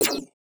Universal UI SFX / Clicks
UIClick_Menu Negative Laser Shot 03.wav